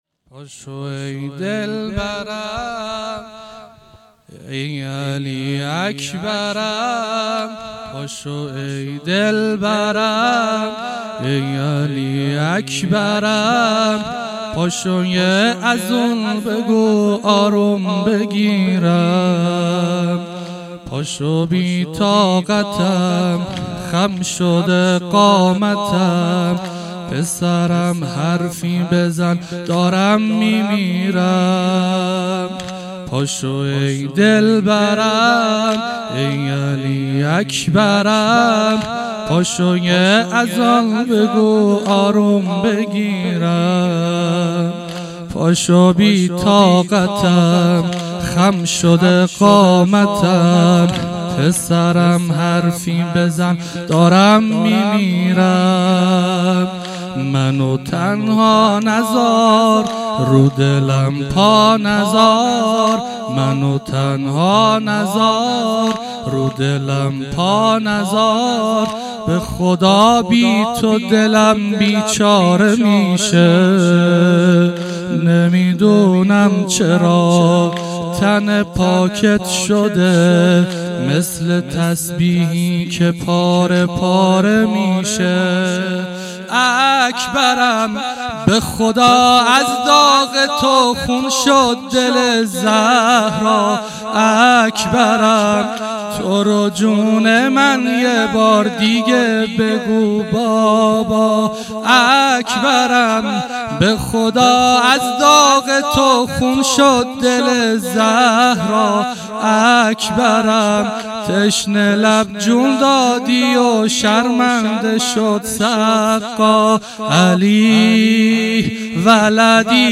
0 0 واحد - پاشو ای دلبرم ای علی اکبرم
شب هشتم - دهه اول محرم 1400